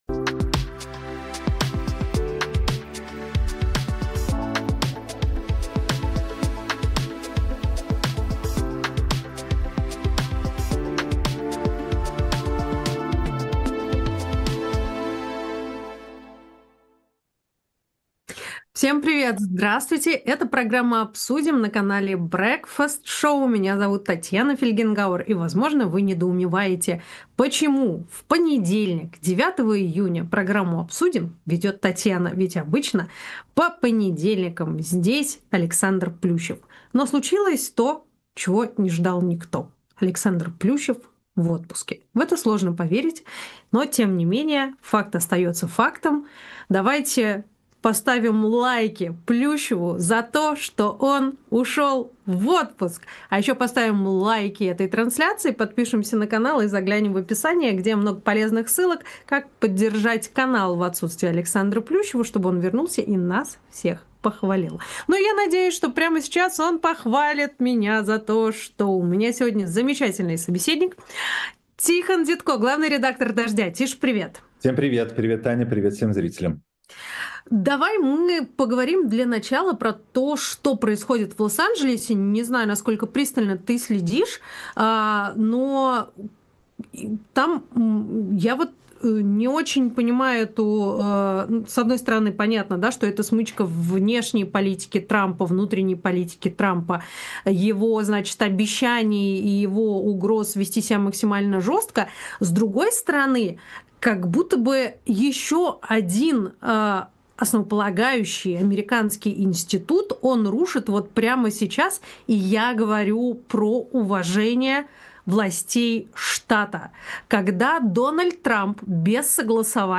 Эфир ведёт Татьяна Фельгенгауэр